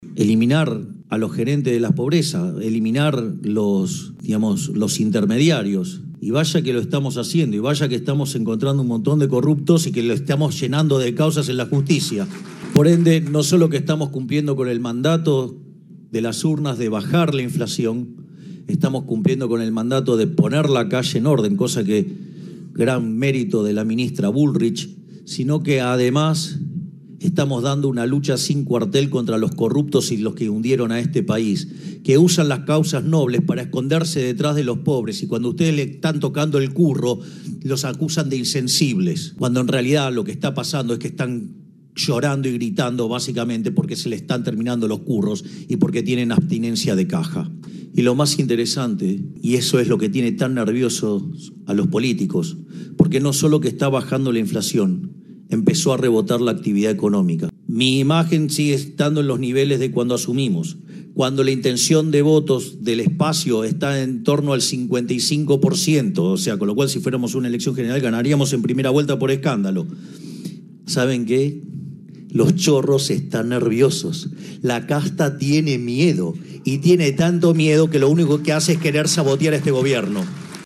Desde su primera visita a la provincia de Santa Fe en calidad de presidente, Javier Milei volvió a referirse a la actividad legislativa de esta semana.
AGROACTIVA-MILEI-SOBRE-ACTIVIDAD-ECONOMICA.mp3